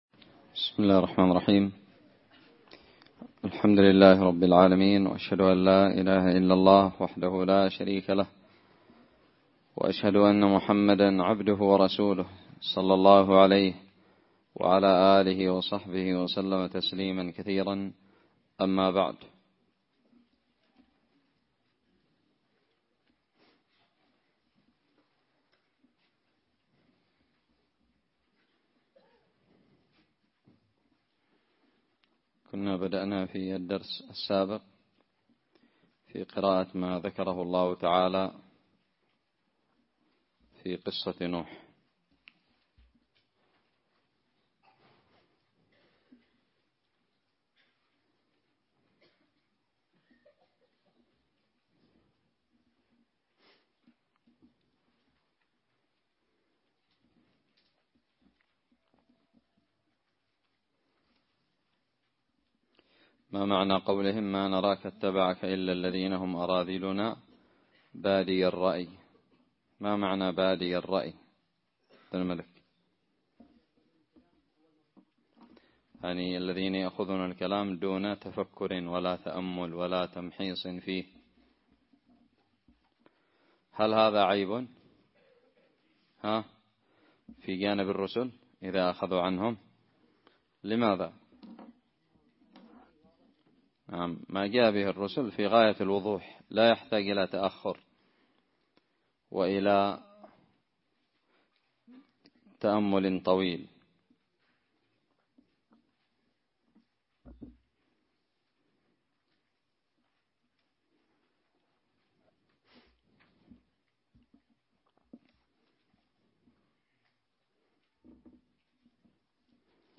الدرس الحادي عشر من تفسير سورة هود
ألقيت بدار الحديث السلفية للعلوم الشرعية بالضالع